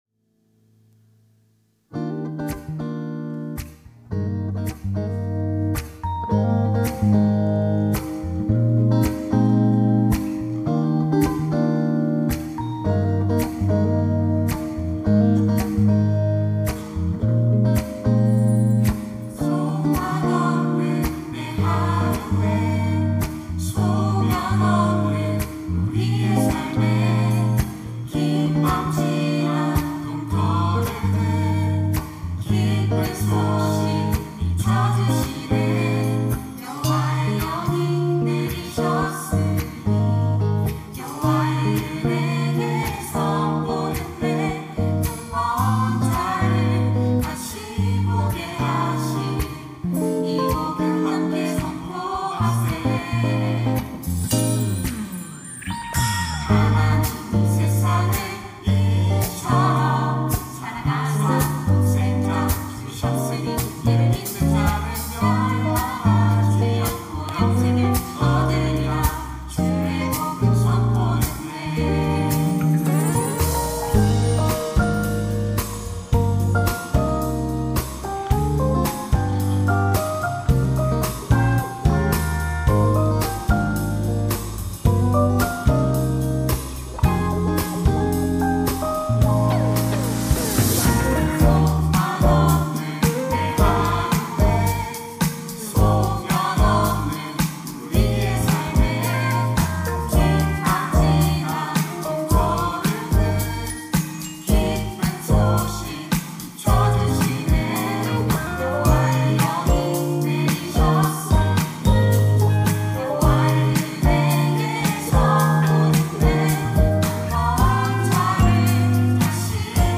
특송과 특주 - 요한복음 3장 16절
청년부 2025년 4팀 12셀